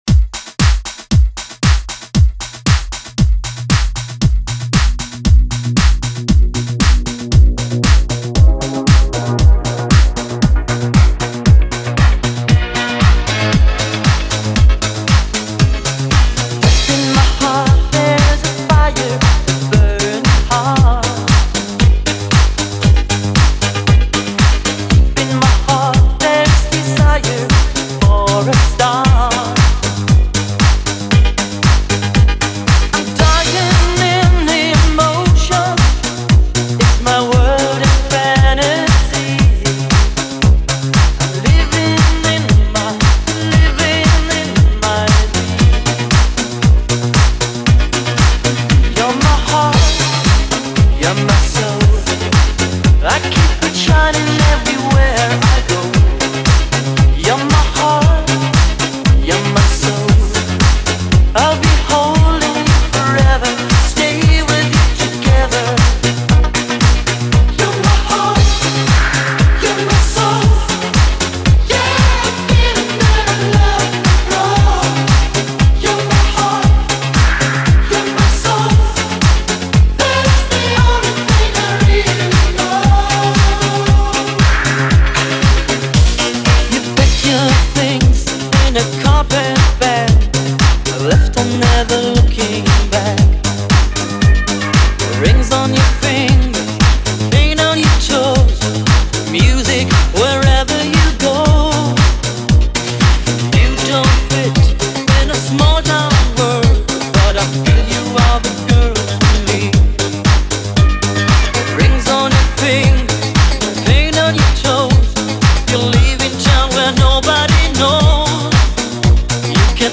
Relive the Iconic Hits with a Miami Party Twist